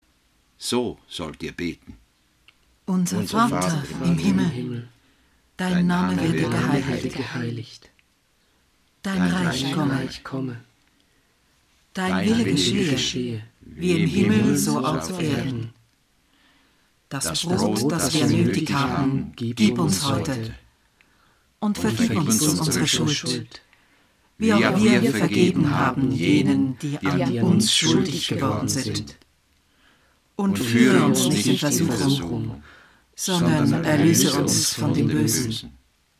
Durchgehende Lesung biblischer Bücher